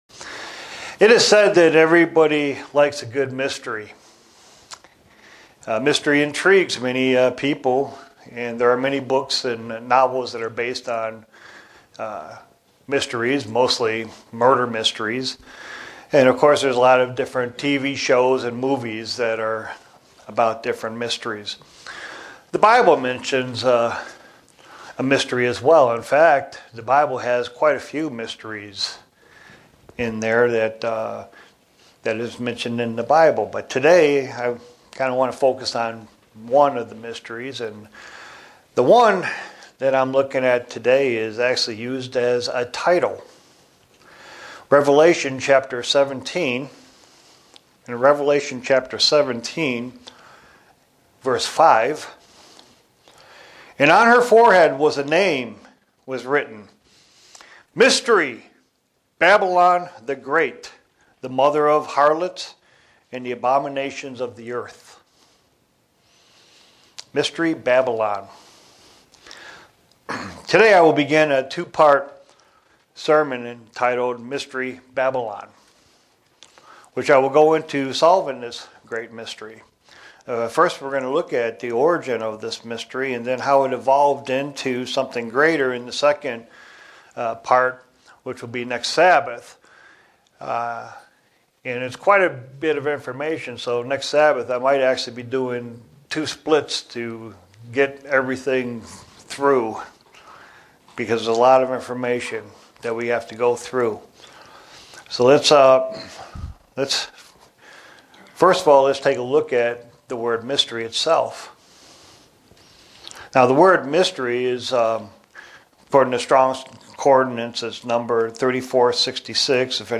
Given in Buffalo, NY
Print To understand this Mystery one has to look first at its origin. sermon Studying the bible?